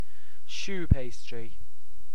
Uttal
Alternativa stavningar chou pastry Uttal UK Ordet hittades på dessa språk: engelska Ingen översättning hittades i den valda målspråket.